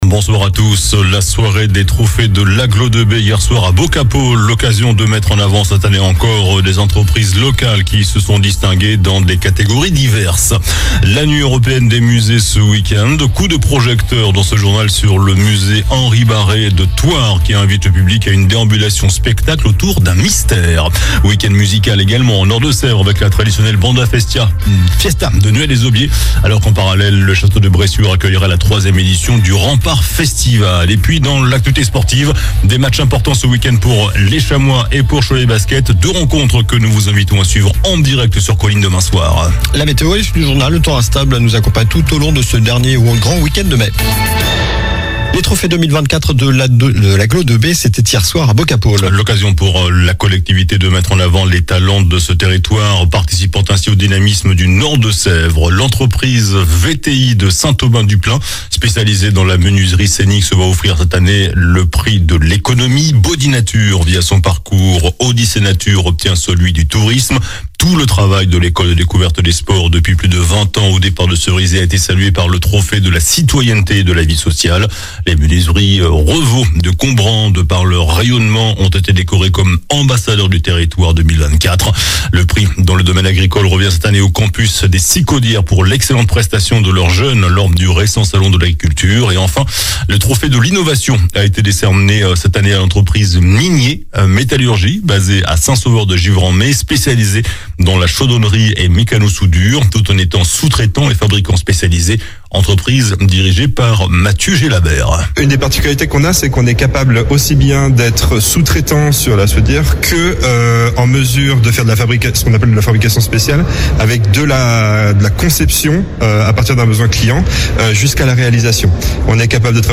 JOURNAL DU VENDREDI 17 MAI ( SOIR )